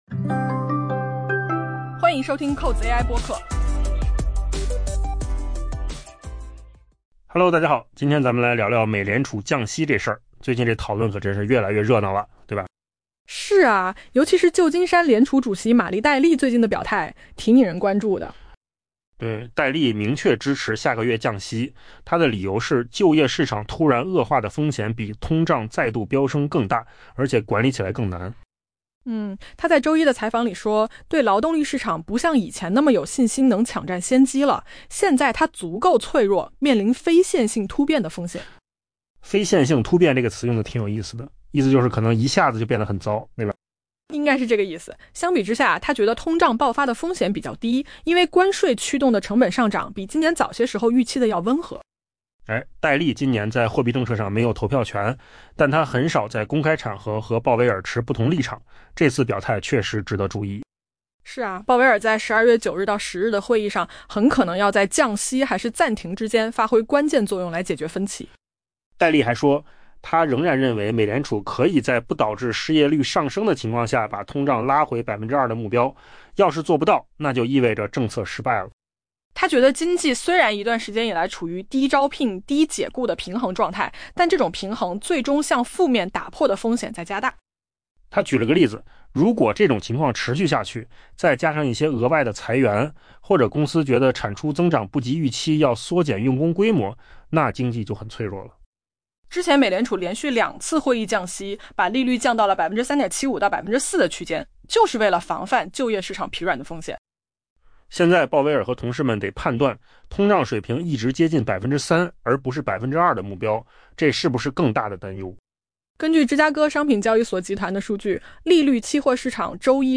【文章来源：金十数据】AI播客：换个方
AI 播客：换个方式听新闻 下载 mp3 音频由扣子空间生成 旧金山联储主席玛丽·戴利 （Mary Daly） 表示，她支持美联储在下个月的会议上降低利率，因为她认为就业市场突然恶化的可能性比通胀再度飙升更大，且管理难度更高。